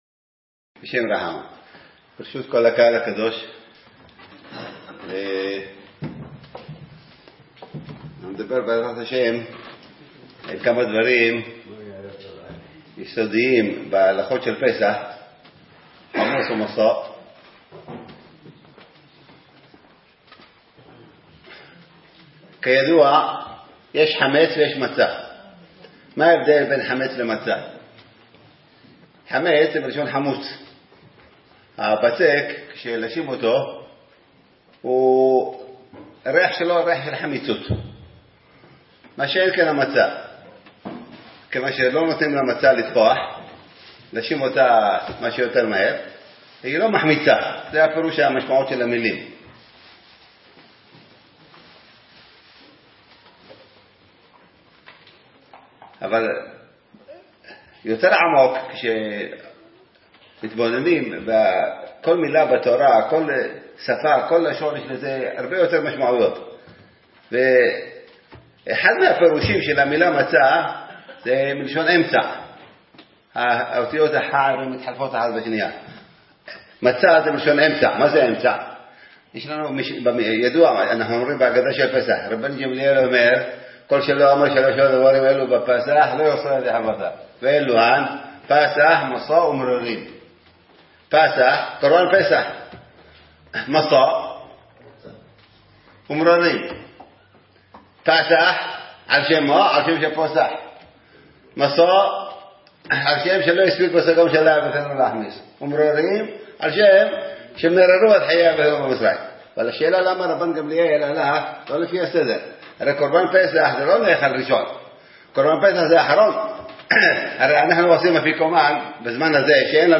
חדש! שיעור בהלכות פסח - חולון ת"ו